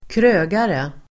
Ladda ner uttalet
Uttal: [²kr'ö:gare]